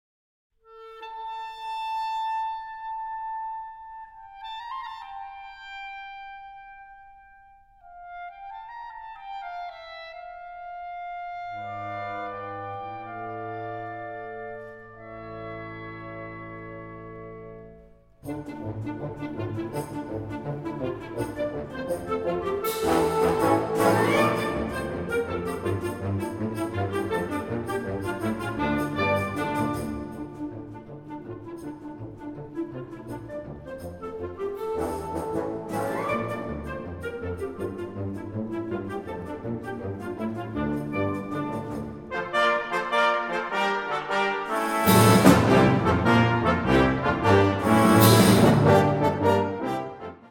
Catégorie Harmonie/Fanfare/Brass-band
Sous-catégorie Suite
Instrumentation Ha (orchestre d'harmonie)
suite pour orchestre à vent